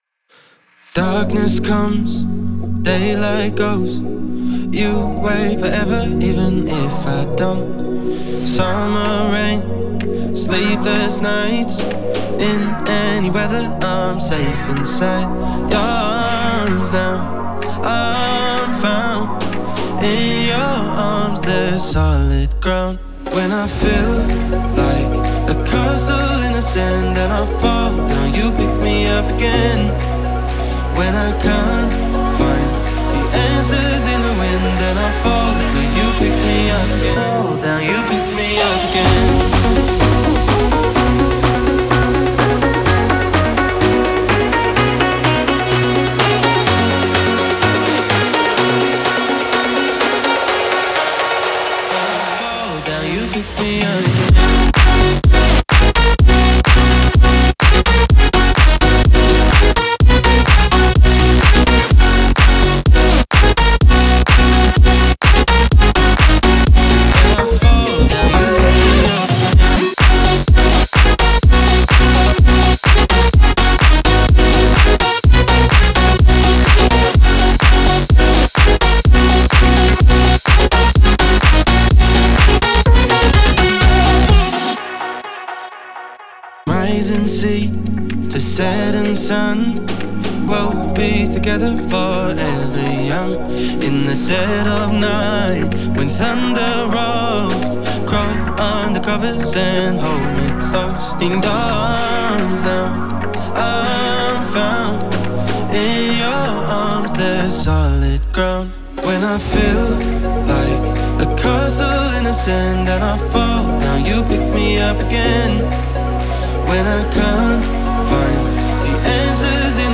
это энергичный трек в жанре хаус